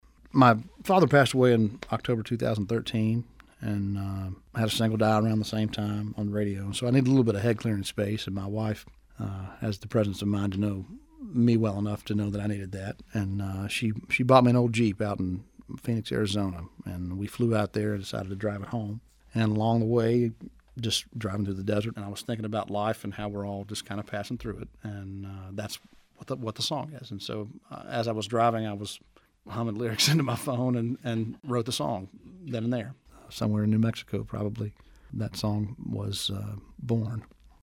Audio / Chris Stapleton explains how he came up with the idea for his single, “Traveller.”